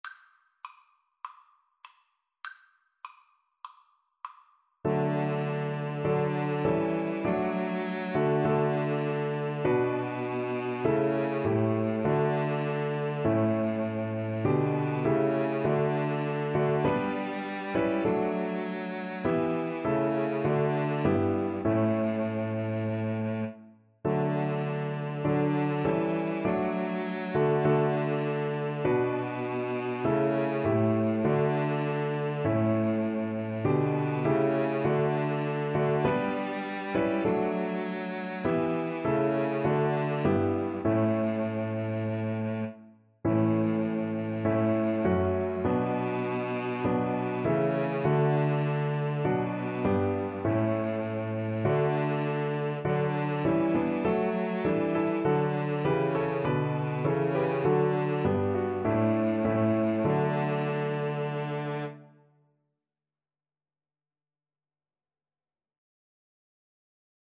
Maestoso
Piano Trio  (View more Easy Piano Trio Music)